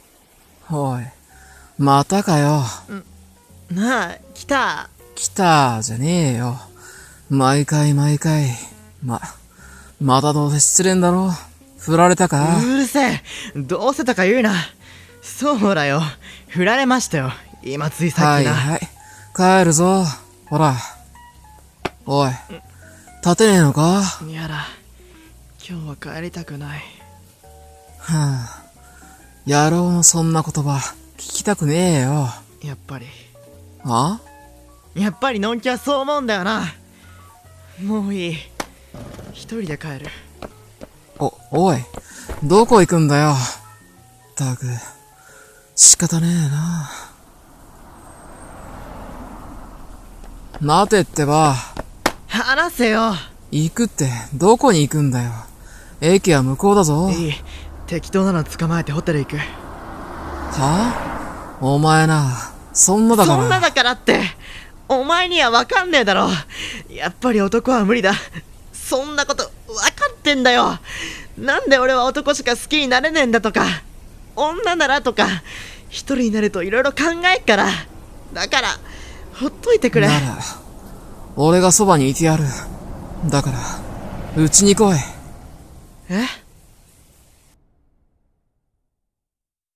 【BL声劇】アイビーが根付く前に【ドラマCD風】